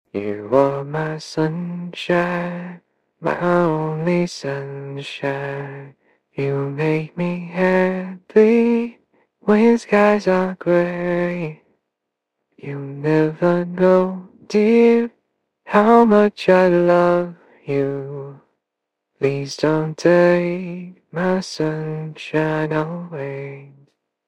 AI Song Cover